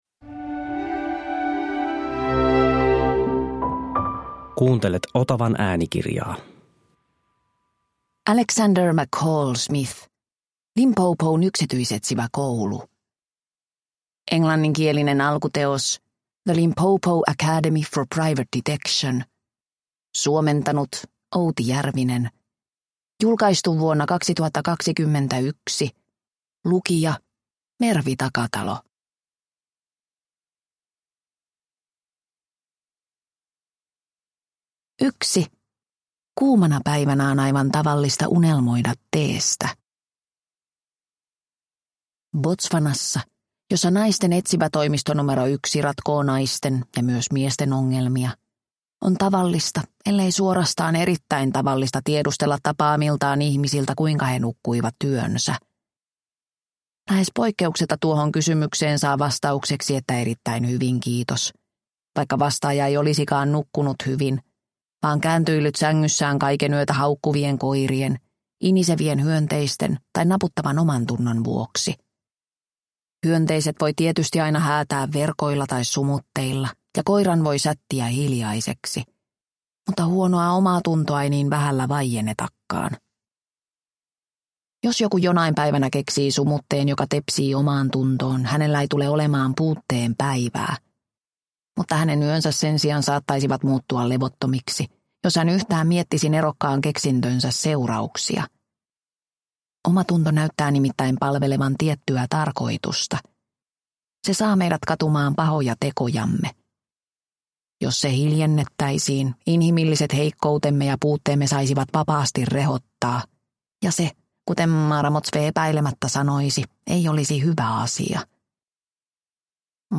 Limpopon yksityisetsiväkoulu – Ljudbok – Laddas ner